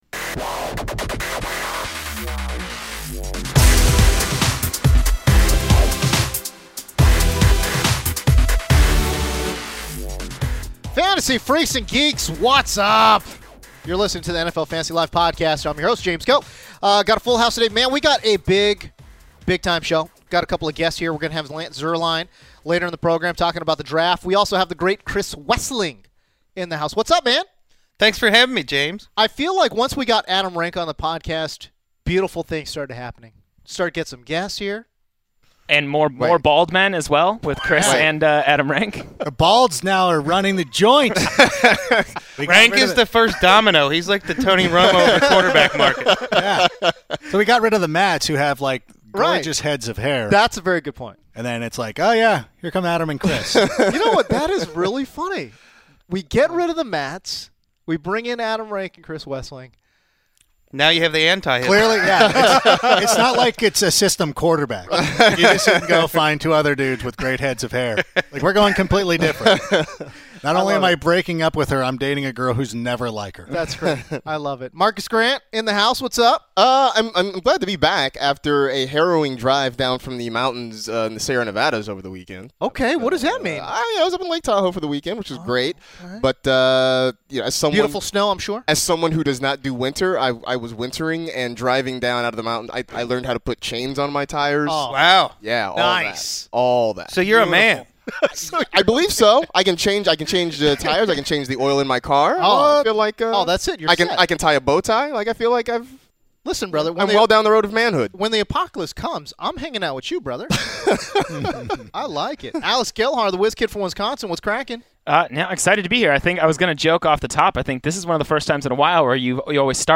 The NFL Fantasy LIVE crew assembles for a big show and welcomes in two high-profile guests: